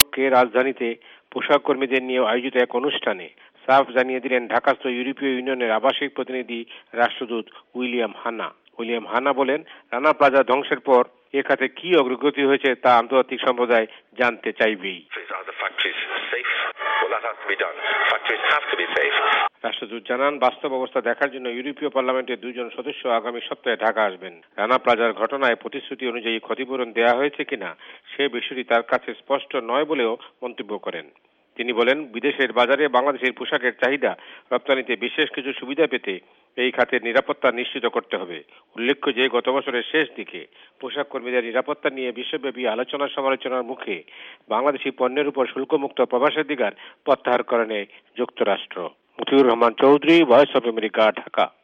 ভয়েস অফ এ্যামেরিকার ঢাকা সংবাদদাতাদের রিপোর্ট